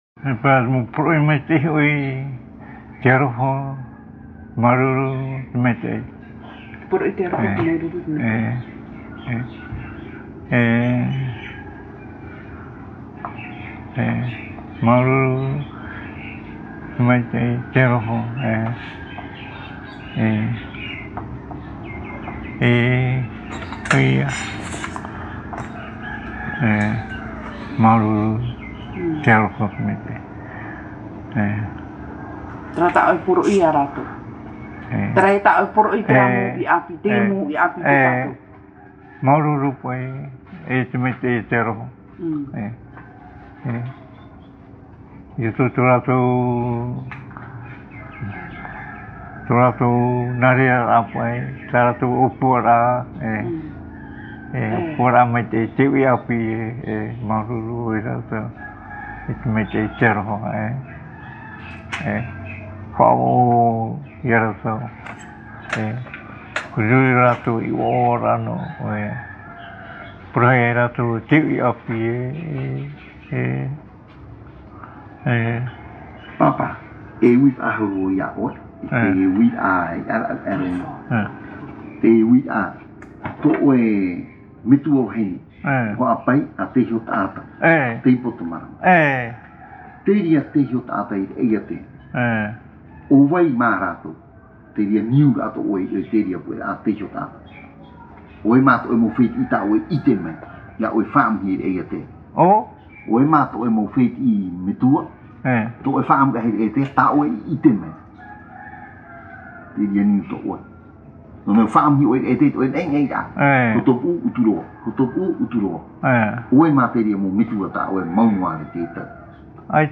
Interview réalisée à Tīpaerui sur l’île de Tahiti.
Papa mātāmua / Support original : cassette audio